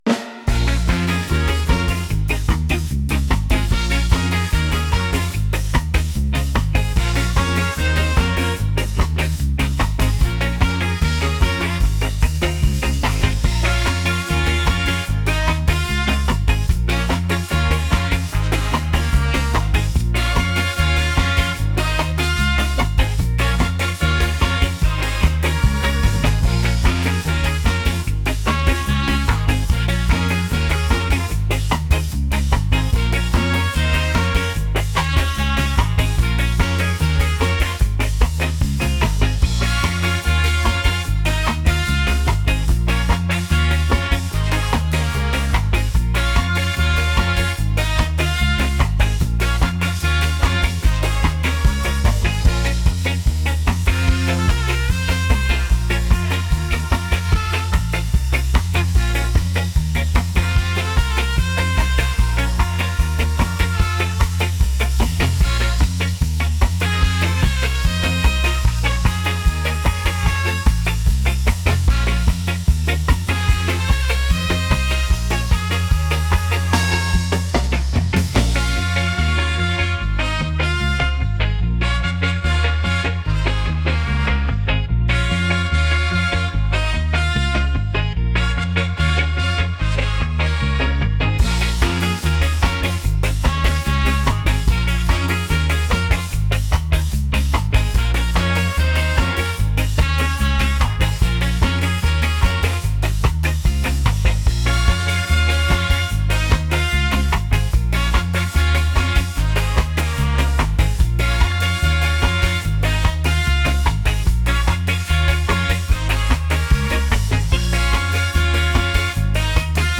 reggae | soul | upbeat